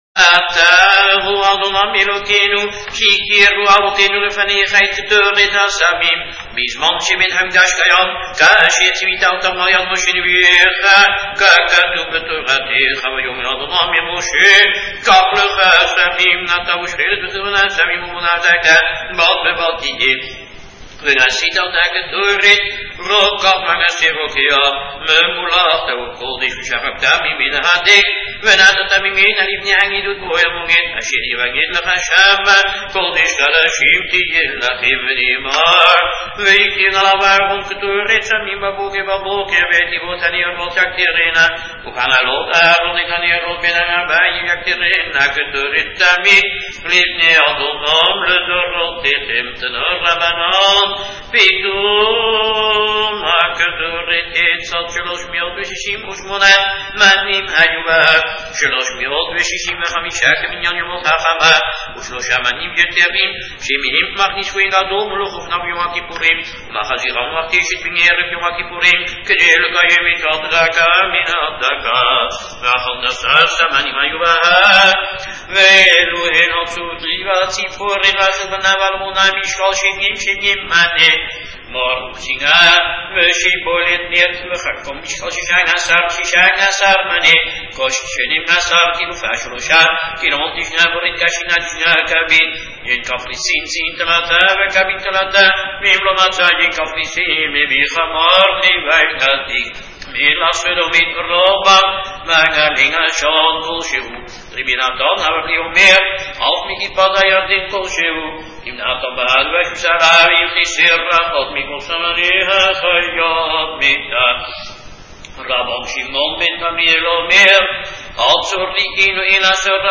Chazzan